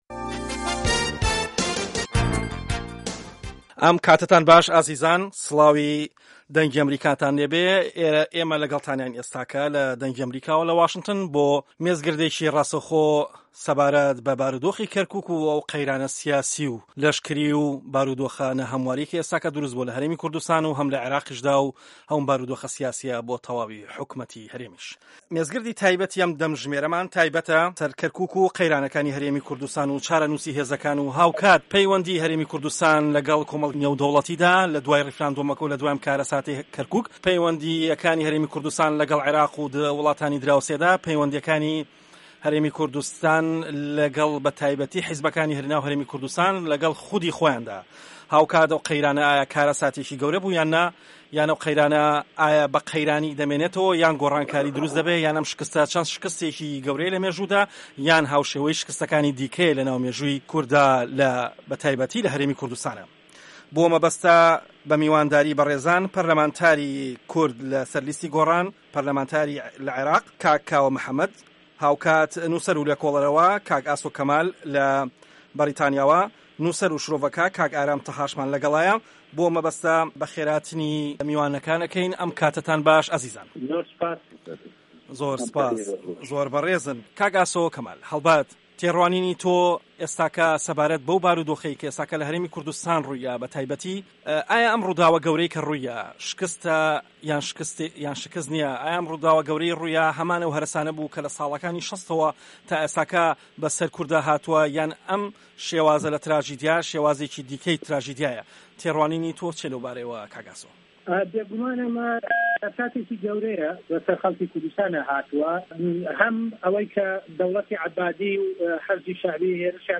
مێزگرد: هەرێمی کوردستان لەبەردەم دەستور و یاساو تراژیدیادا